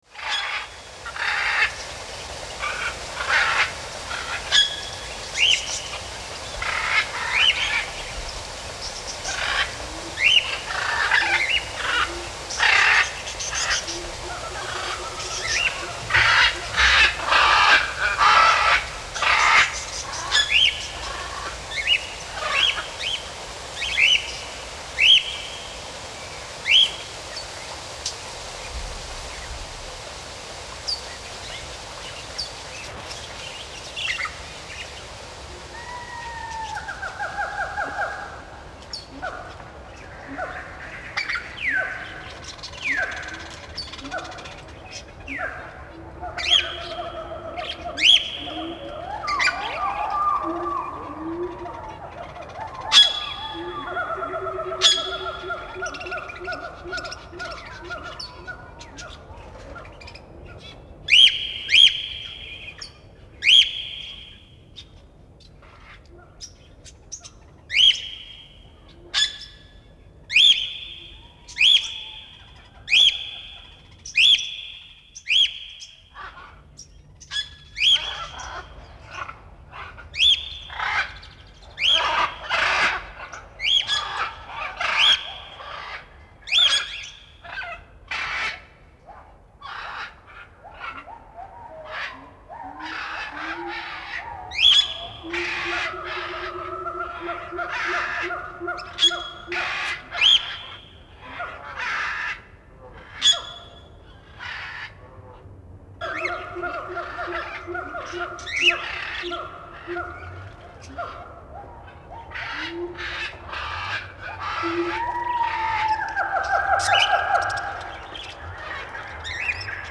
Тропический ливень
Даже не верится,что эти звуки искусственные.